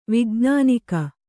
♪ vijṇānika